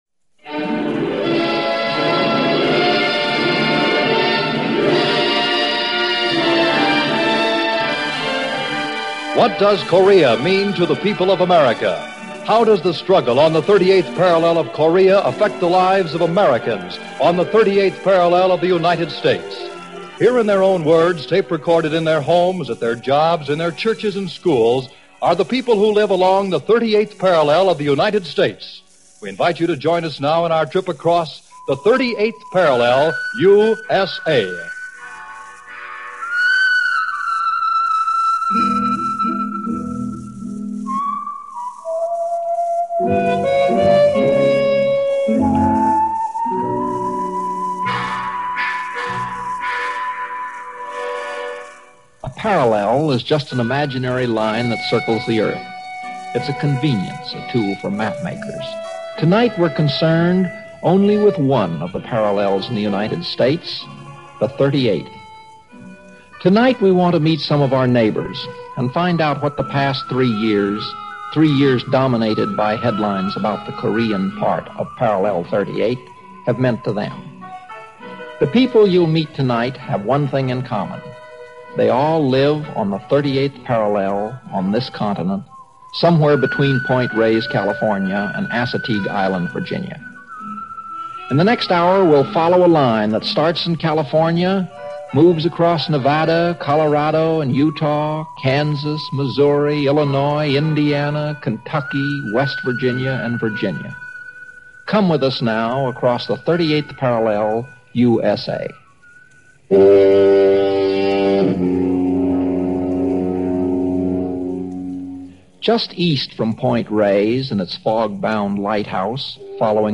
Narrated by Wil Rogers Jr. and broadcast around the time of the armistice signing, “38th Parallel: USA” was a word picture of life along the 38th Parallel in the U.S. and how the war impacted on the people living on that dividing line.
CBS was one of the innovators in using on-the-spot recording for News and Public Affairs.